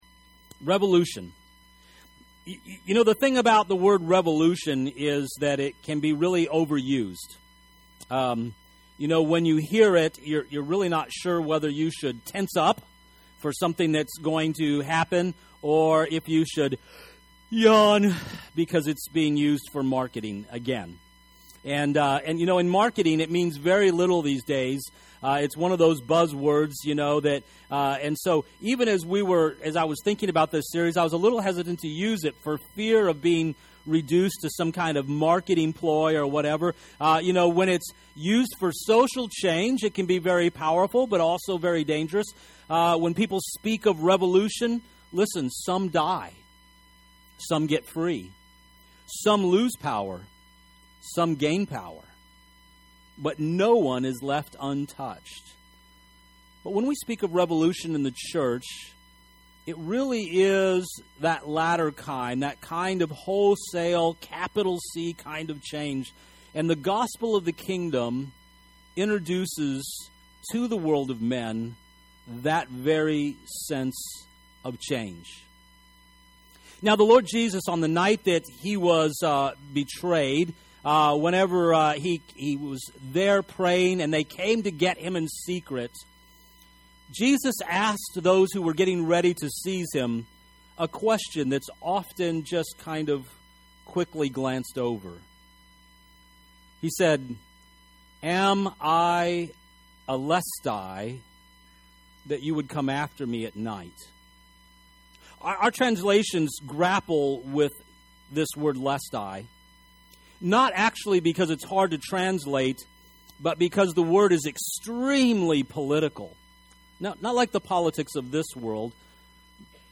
Revolution speaks of real change, the kind that cannot be domesticated, that is not convenient, and will turn lives upside down. This first message in the series points toward the radical idea that the sermon on the mount isn’t just platitudes, or worse yet, a new law to be kept, but assumes that the revolution of the kingdom turns the kingdoms of this world and our lives upside down.